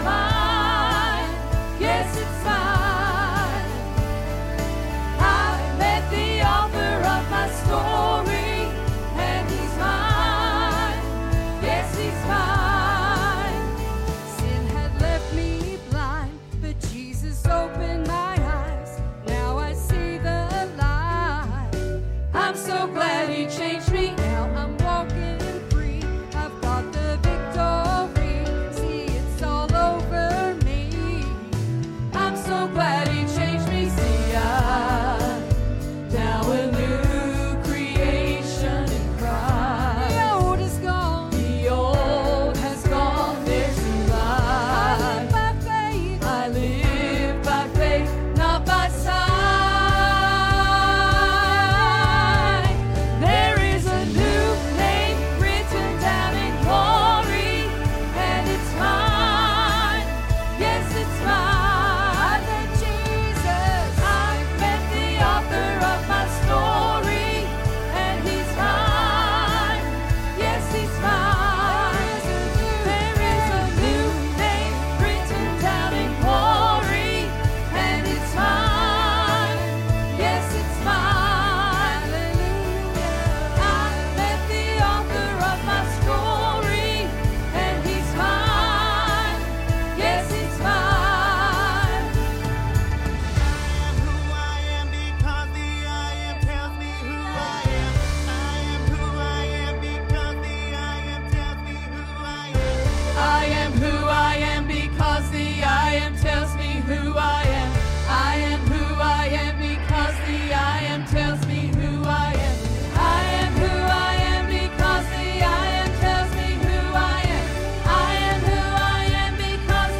In the Last Night of this Wonderful Revival